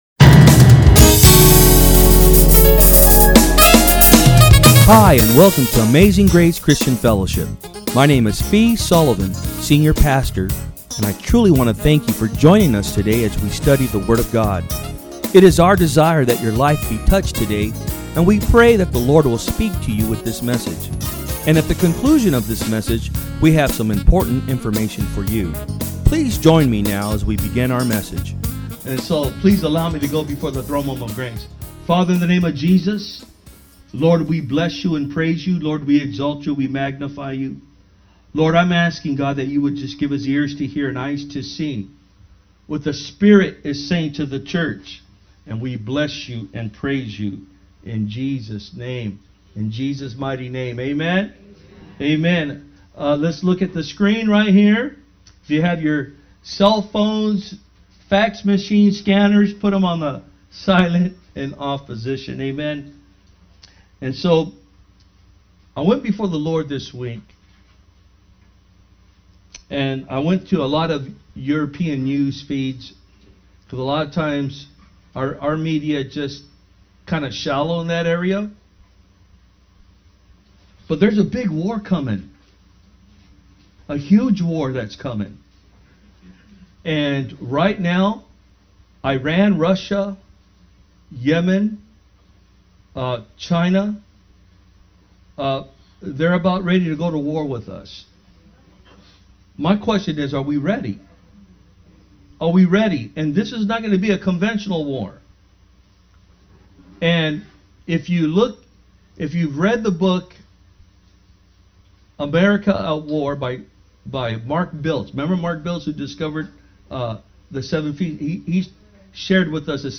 Sermons
From Service: "Sunday Am"